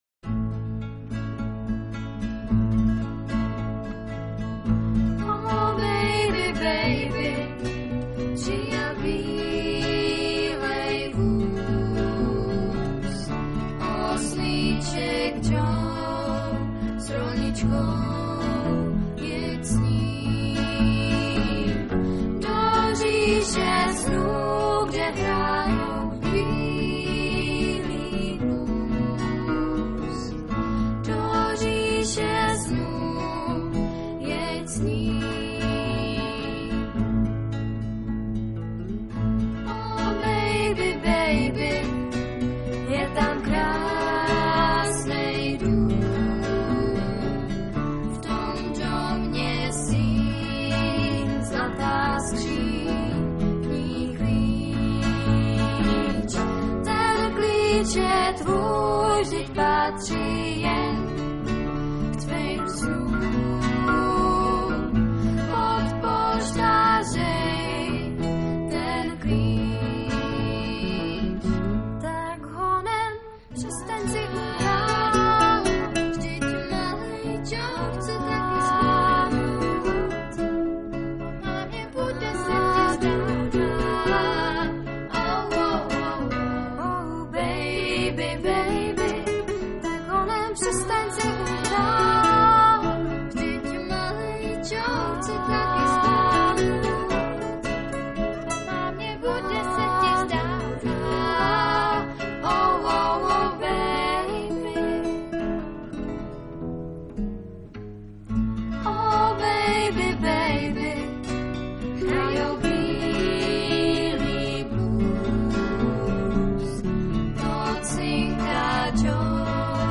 TÁBOR/SEZIMOVO ÚSTÍ - V loňském roce na táborské Bambiriádě 2006 opět vystoupili vítězové Dětské porty z Českého Krumlova - kapela LÉTAVICE z Českých Budějovic